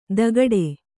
♪ dagaḍe